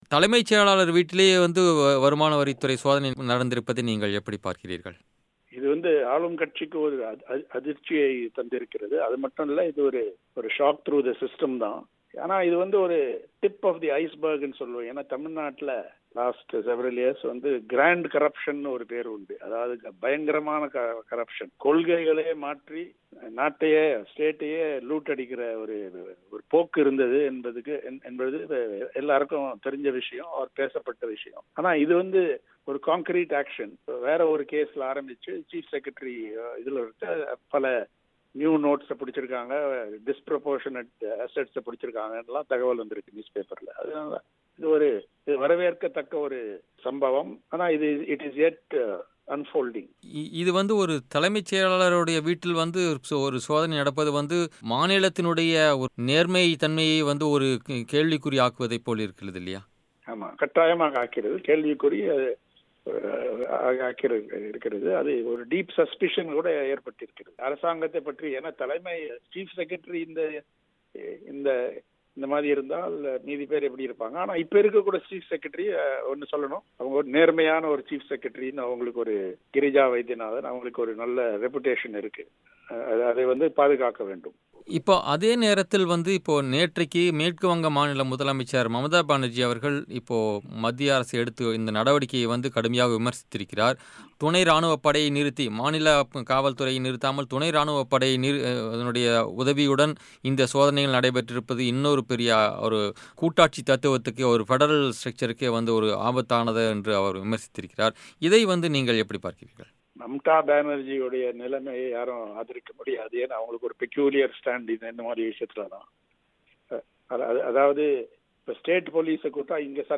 தமிழக அரசின் தலைமைச் செயலர் ராம மோகன ராவ் வீட்டில் நடந்த வருமான வரிச் சோதனை, முடிவல்ல, ஆரம்பம்தான் என்கிறார் மூத்த பத்திரிகையாளர் என். ராம். இதுகுறித்து, பிபிசி தமிழுக்கு அவர் அளித்த பேட்டி.